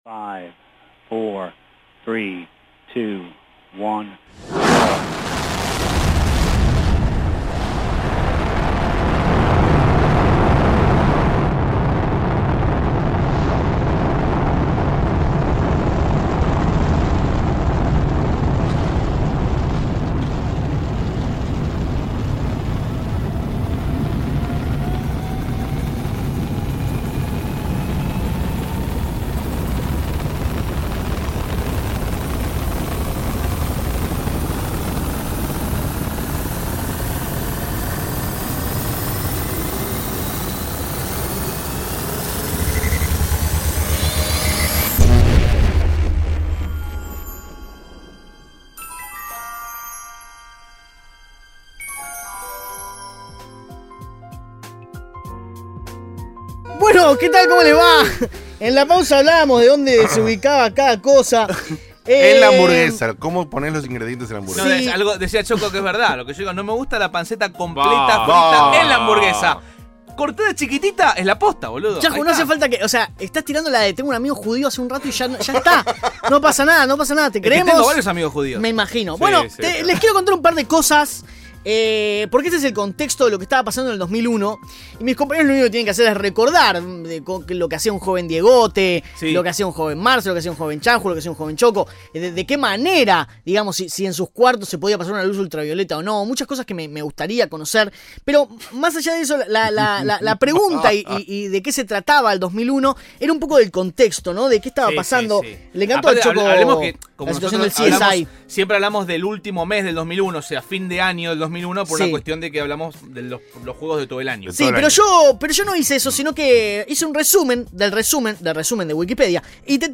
Nos sumergimos en un viaje extrasensorial al 2001, horrible año para los argentinos, pero no asi para el gaming. tuvimos un pequeño error de audio, y la "apertura" del programa no salio (igual eran menos de 12 minutos solo de la presentacion) el contenido del programa esta intacto gracias a dios.